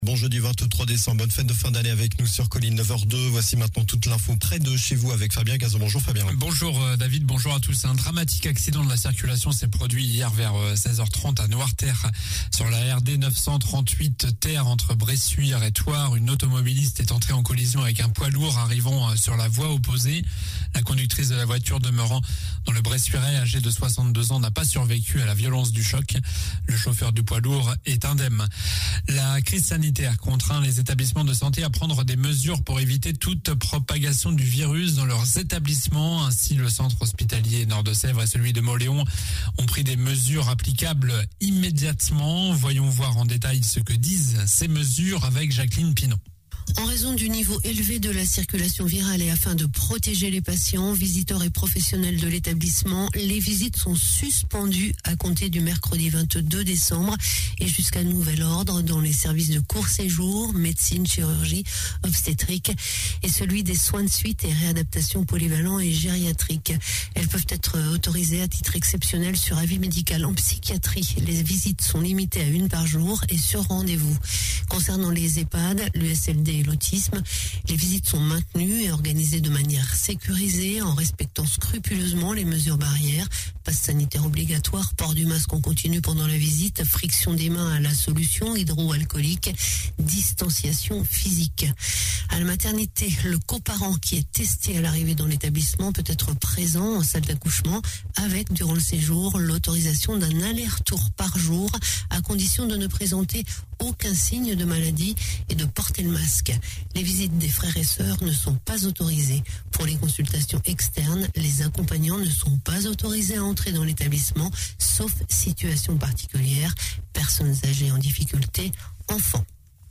Journal du jeudi 23 décembre